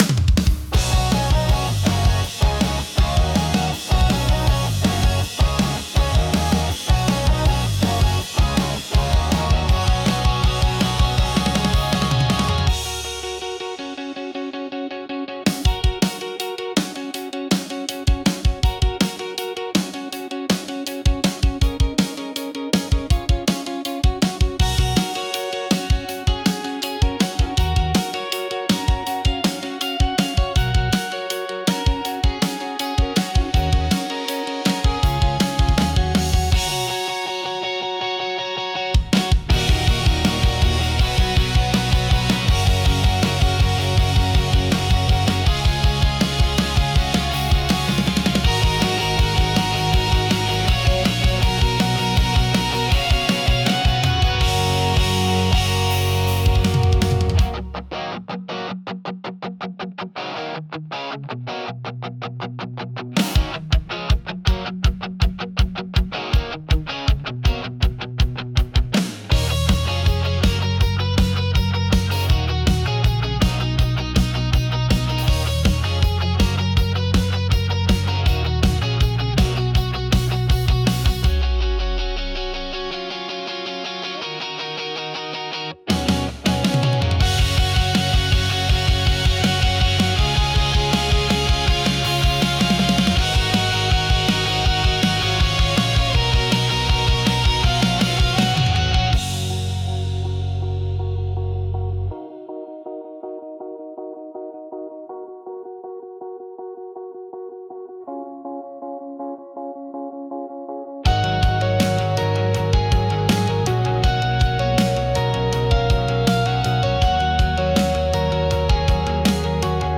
Genre: Pop Punk Mood: High Energy Editor's Choice